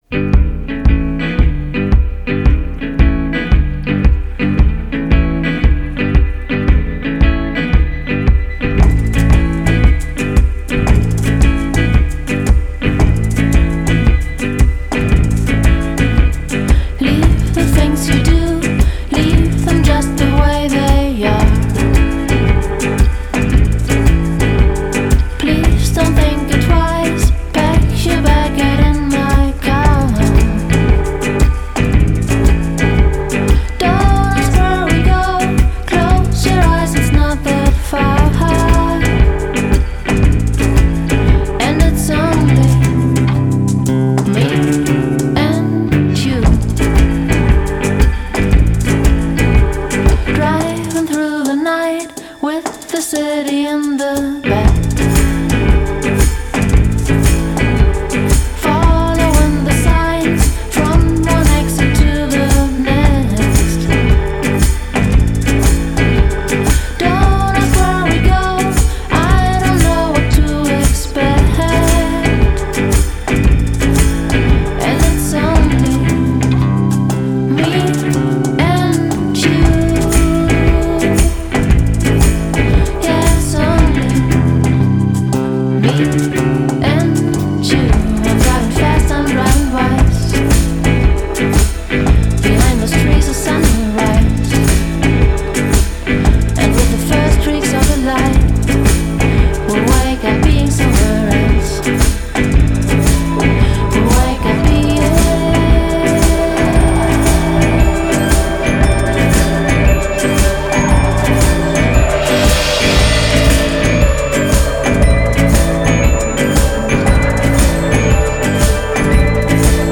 Genre: Indie